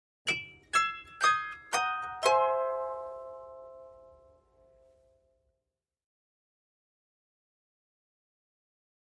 Harp, Descending Arpeggio In Two Voices, Type 3